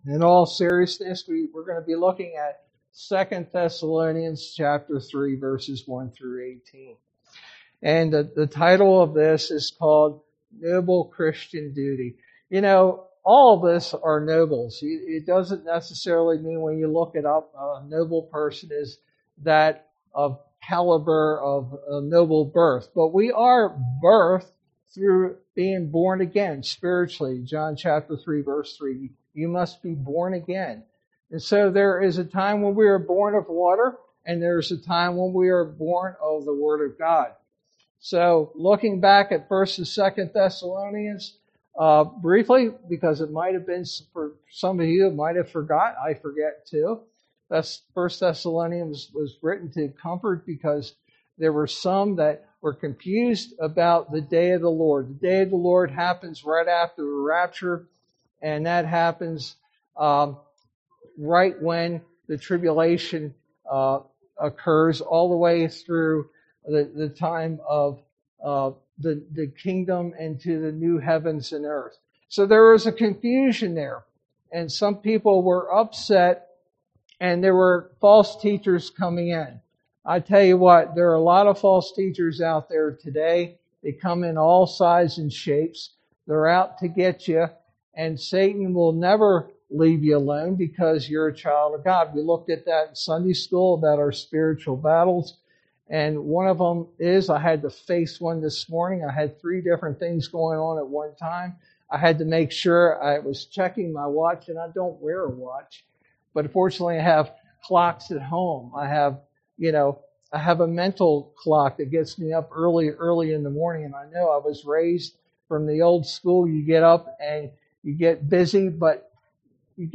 sermon verse: 2 Thessalonians 3:1-18